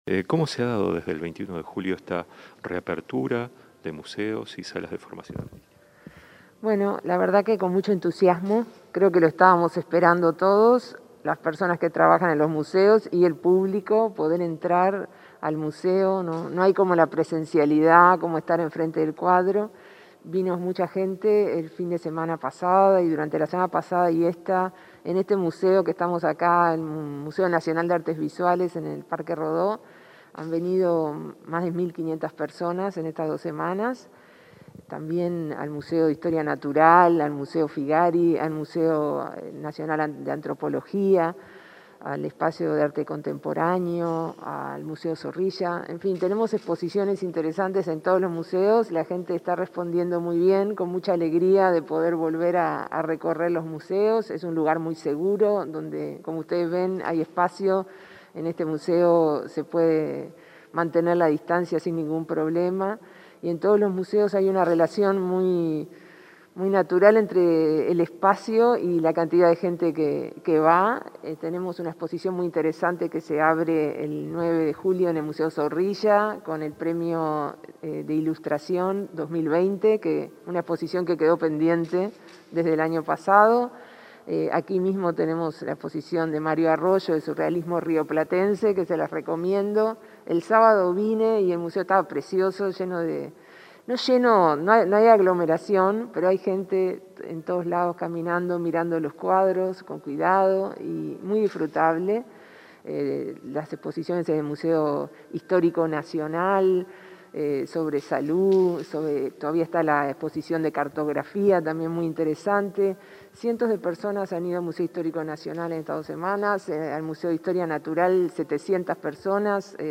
Entrevista a la directora Nacional de Cultura, Mariana Wainstein